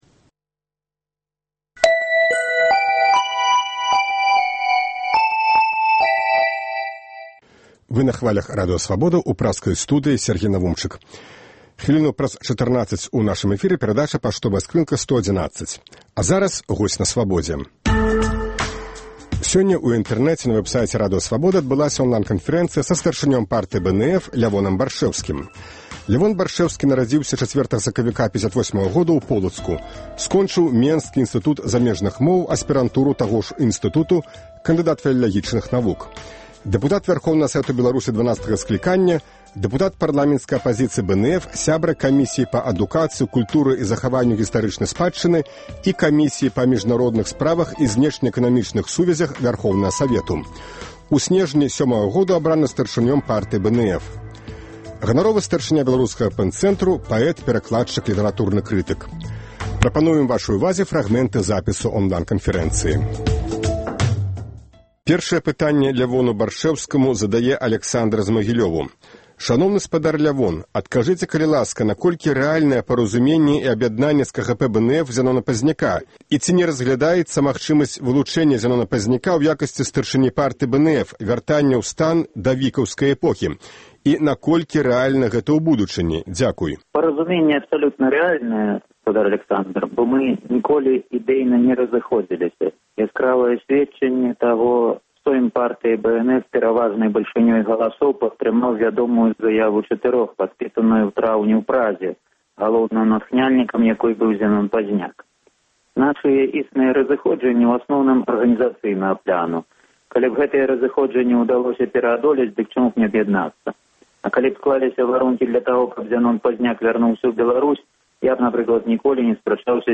Онлайн-канфэрэнцыя
Запіс онлай-канфэрэнцыі са старшынём Партыі БНФ Лявонам Баршчэўскім